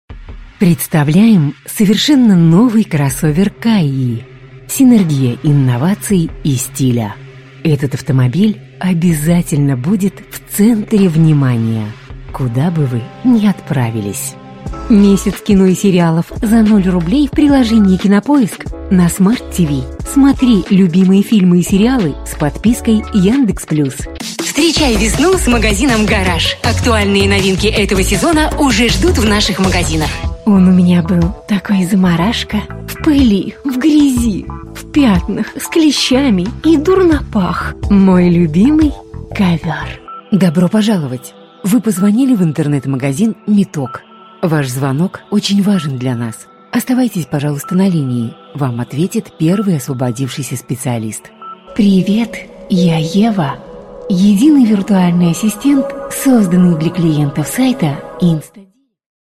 Kadın | Genç Yetişkin,Kadın | Yaşlı,Kadın | Orta Yaş alanlarında ve Telefon Santrali,Karakter